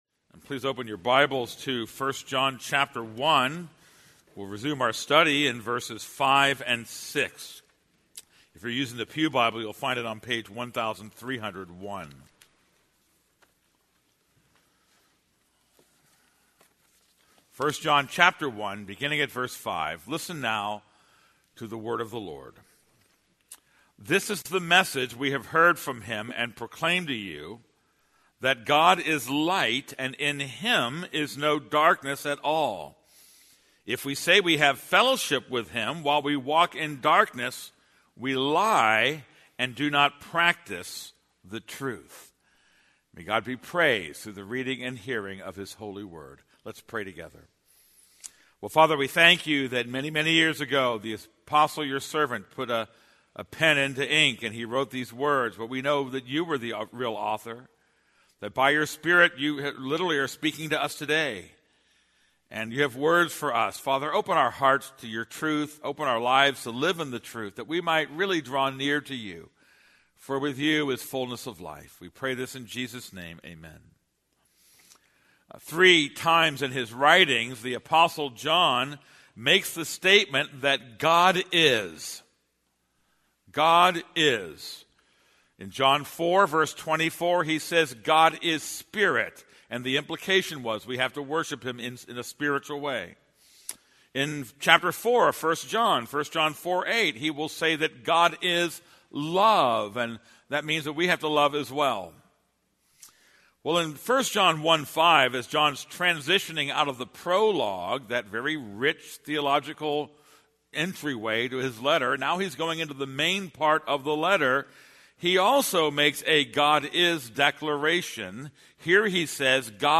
This is a sermon on 1 John 1:5-6.